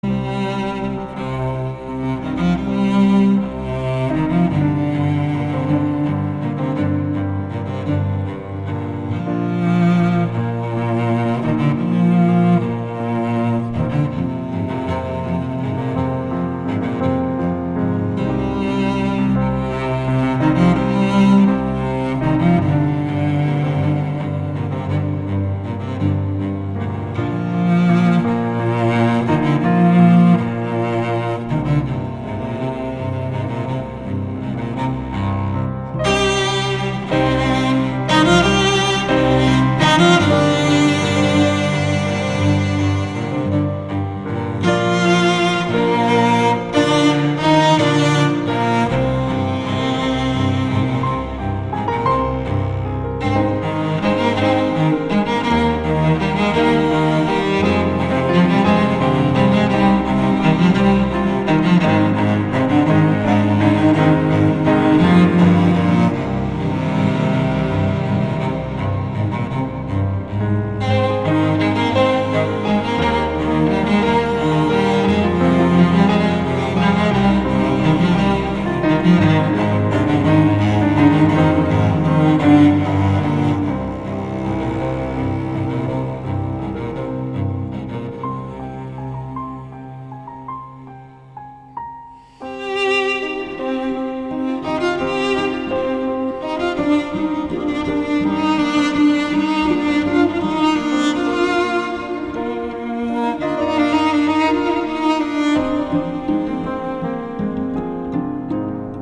Los instrumentos de cuerda frotada son instrumentos cordófonos done el sonido viene producido por las vibraciones de las cuerdas estimuladas por un arco dónde vienen tensada una pequeña mata de crines de caballo.
violoncello.mp3